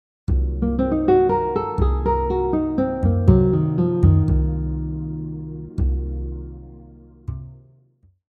Example 3 incorporates the half diminished arpeggio with other scale and chromatic notes.
half-diminished-arpeggio-example-3.mp3